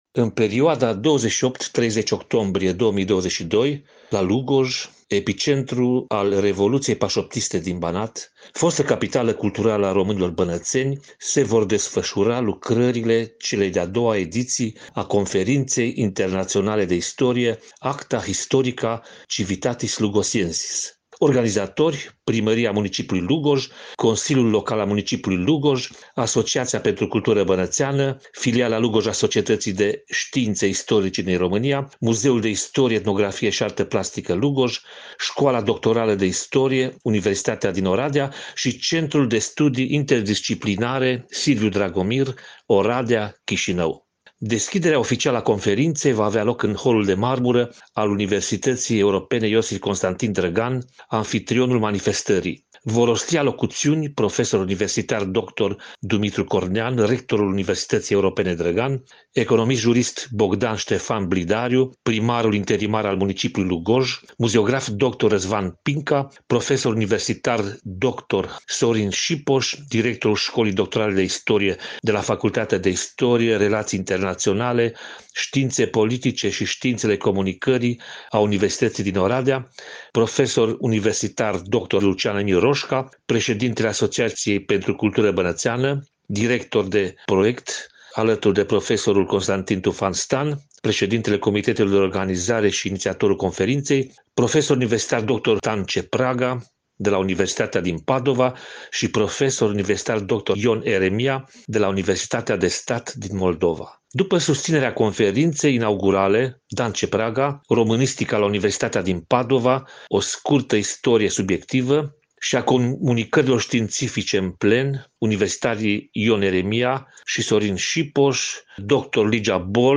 Detalii despre a doua ediție a Conferinței Internaționale de Istorie „Acta Historica Civitatis Lugosiensis”, în interviul acordat, în exclusivitate pentru Radio Timișoara